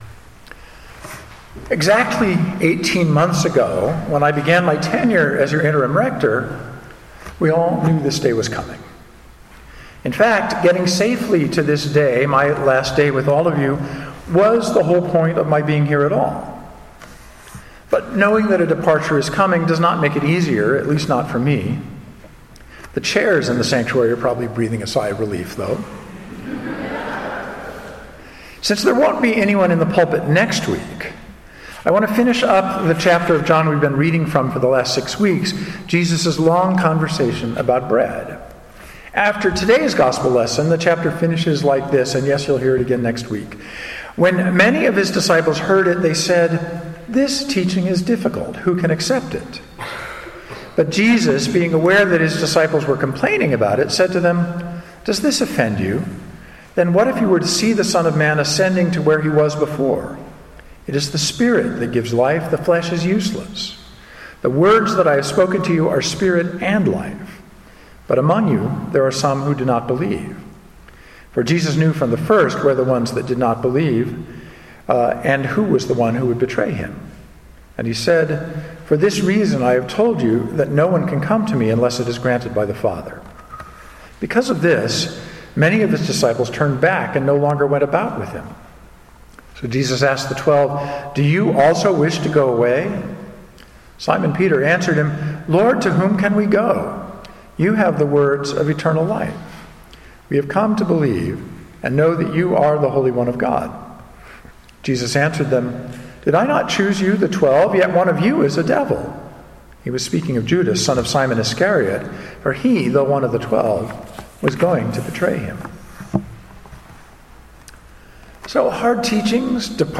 A Sermon for the Twelfth Sunday after Pentecost 2015